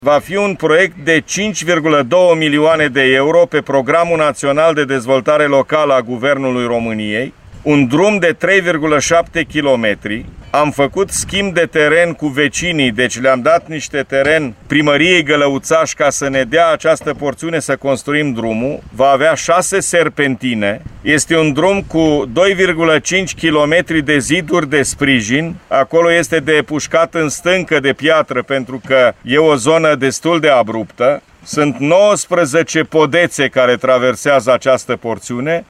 Președintele Consiliului Județean Suceava GHEORGHE FLUTUR a declarat că alte lucrări de refacere a carosabilului vor fi efectuate în Pasul Bilbor, spre județul Harghita.